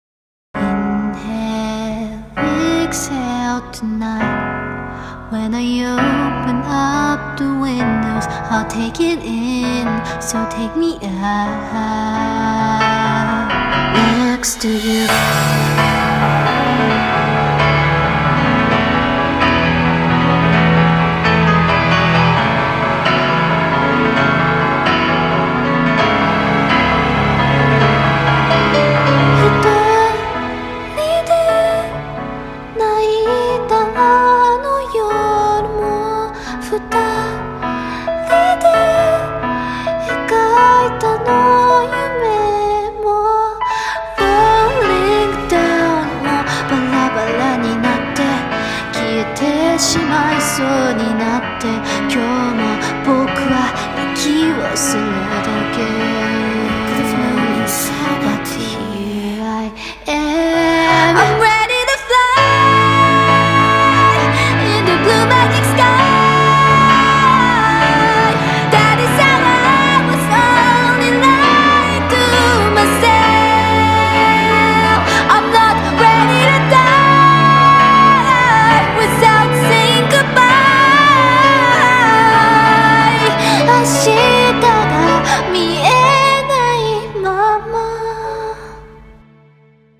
声劇【終わりを謳う